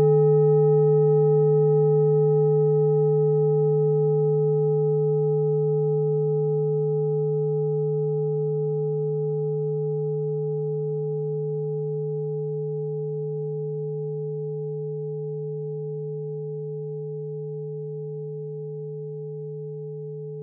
Planetentonschale: Hopiton Klangschale aus Orissa Nr.23 7.Chakra (Scheitelchakra)
Klangschale Orissa Nr.23
Sie ist neu und wurde gezielt nach altem 7-Metalle-Rezept in Handarbeit gezogen und gehämmert.
(Ermittelt mit dem Filzklöppel oder Gummikernschlegel)
Die Frequenz des Hopitons liegt bei 164,8 Hz und dessen tieferen und höheren Oktaven. In unserer Tonleiter liegt sie beim "E".
klangschale-orissa-23.wav